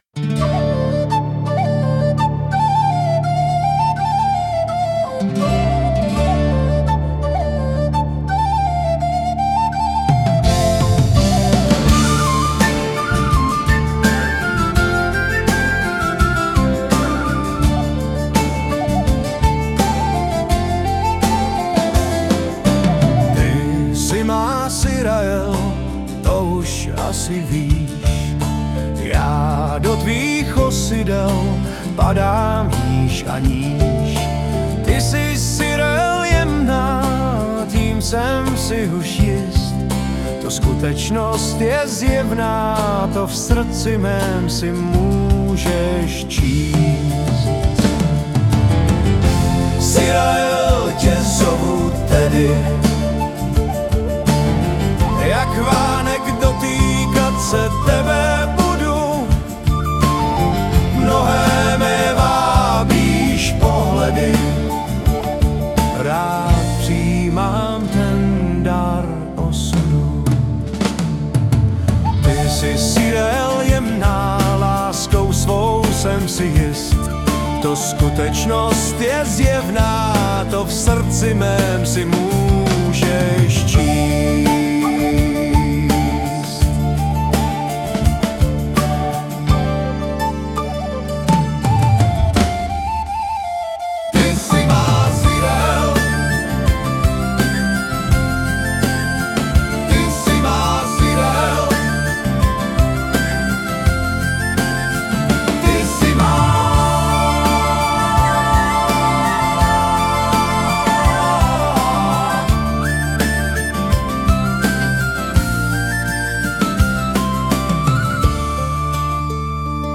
hudba, zpěv sice jsou, ale nechám to tak ;-)